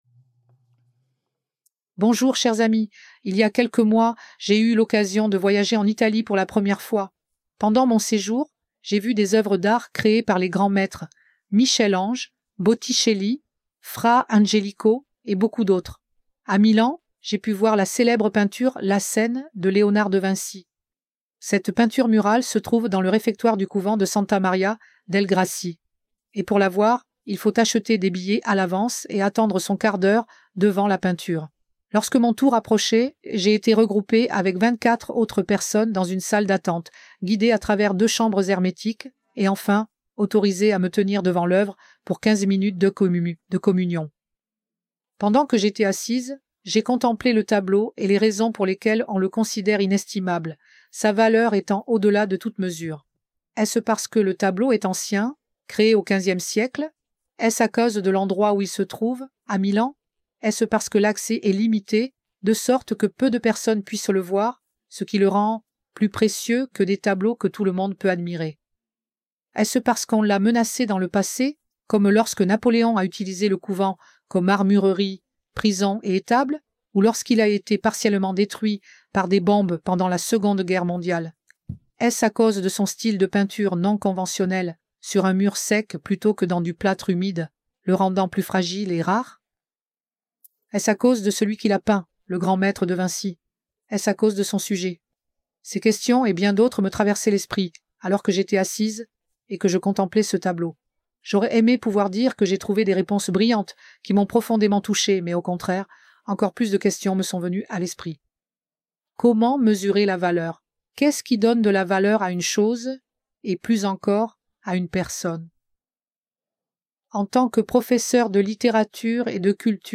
Réunion spirituelle